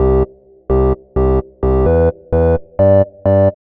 House Organ_129_C.wav